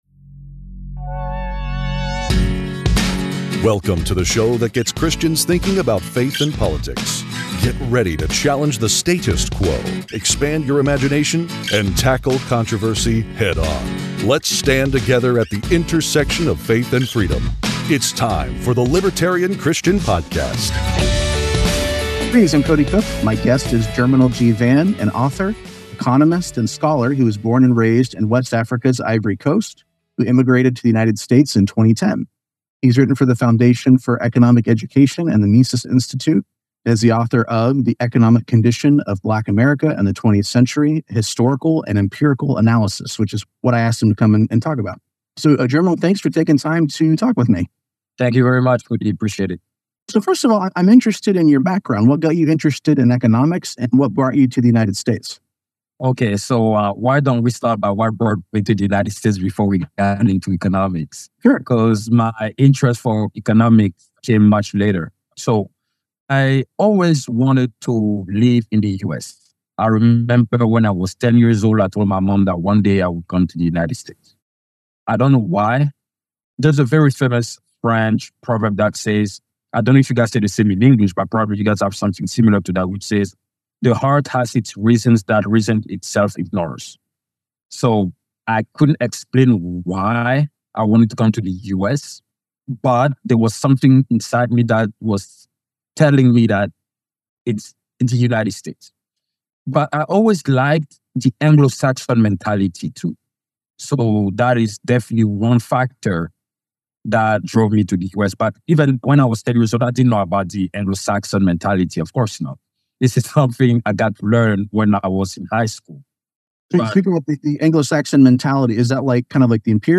Join us for an engaging and intellectually stimulating conversation that challenges prevailing narratives and explores paths to a more equitable society.